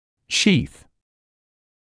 [ʃiːθ][шиːс]оболочка; влагалище